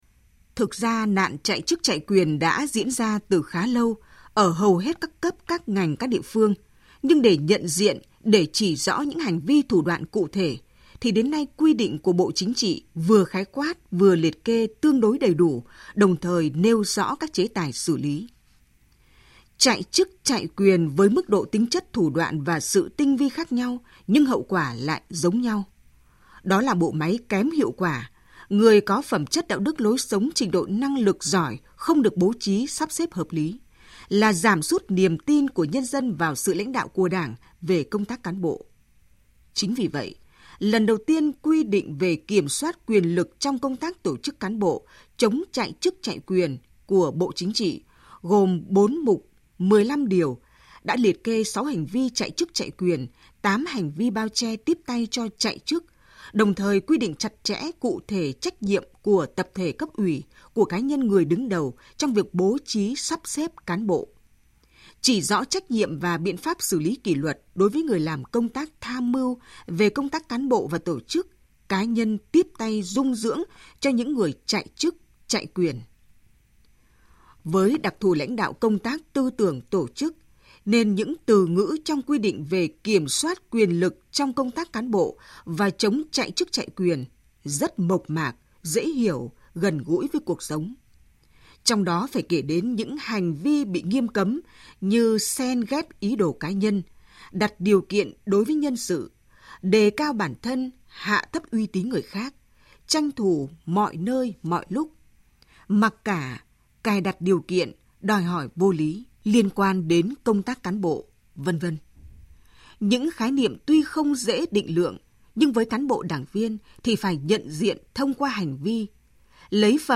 THỜI SỰ Bình luận VOV1